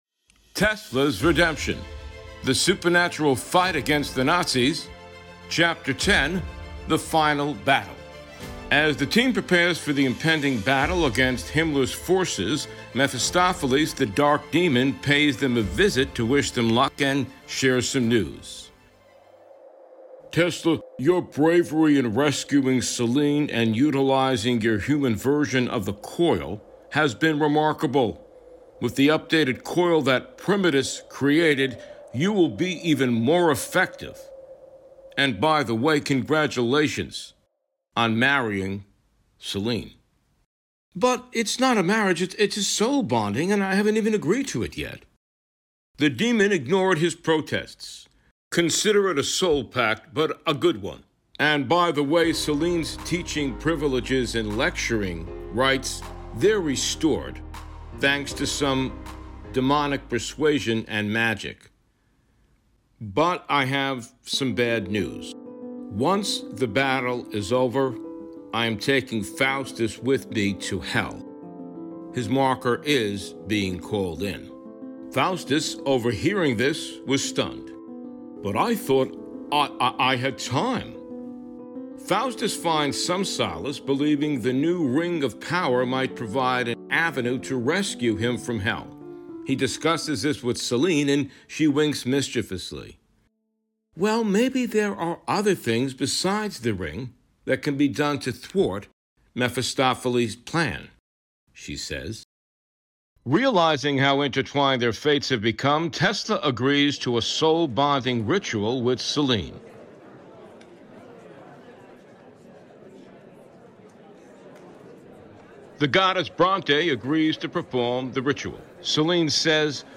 Format: Audio Book
Voices: Solo
Narrator: Third Person
Soundscape: Sound effects & music